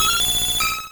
Cri de Persian dans Pokémon Rouge et Bleu.